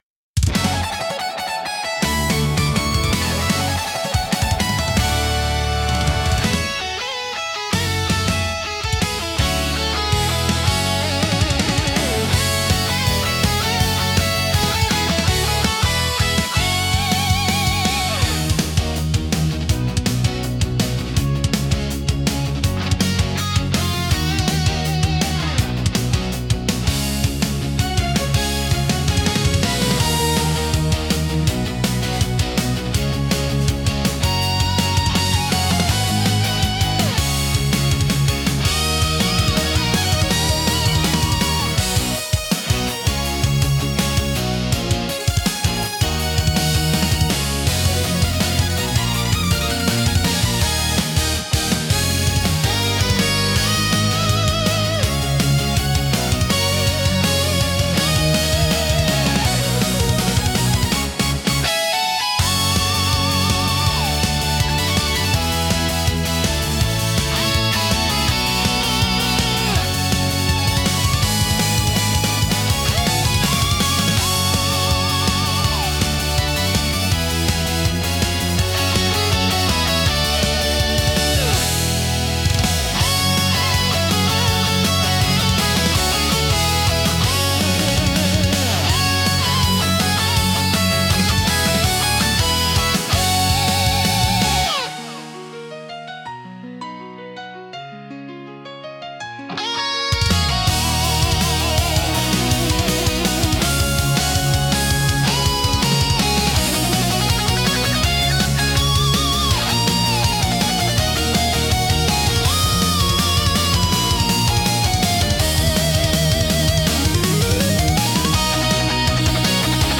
BGMとしては、ゲームや映像の戦闘シーンに最適で、激しいアクションと感情の高まりを盛り上げます。